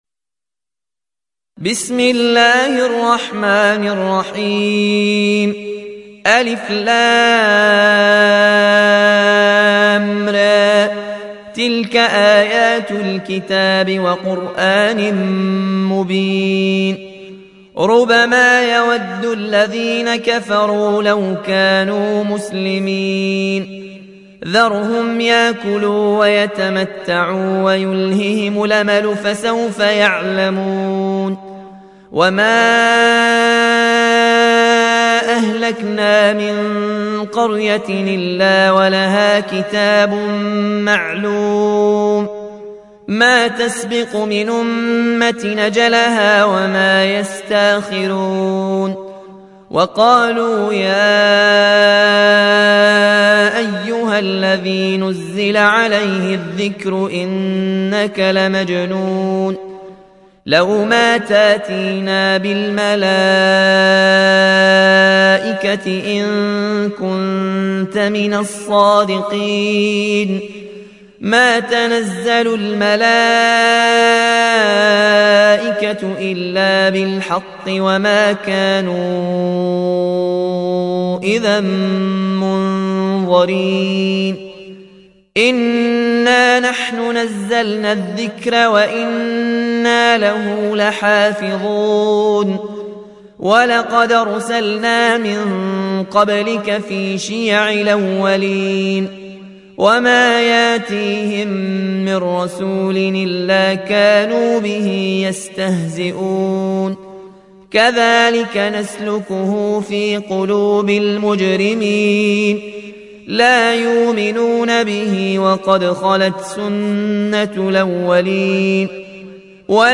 (رواية ورش)